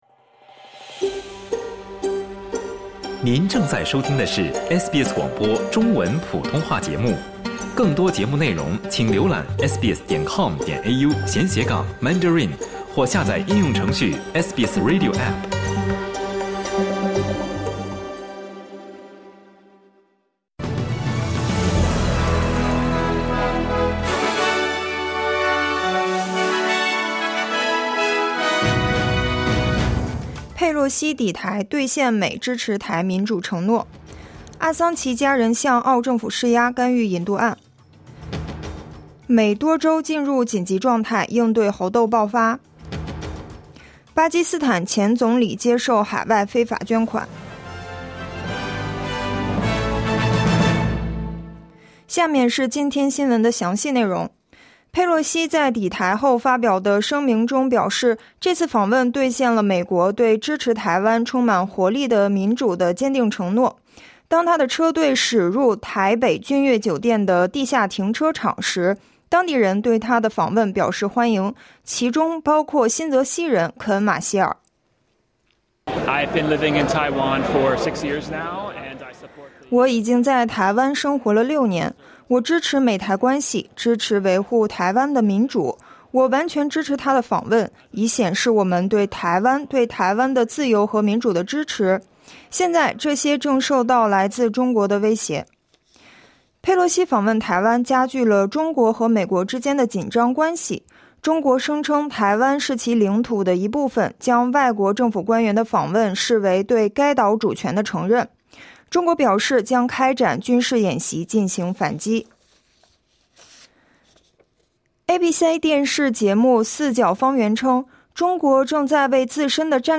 SBS早新闻（8月3日）
请点击收听SBS普通话为您带来的最新新闻内容。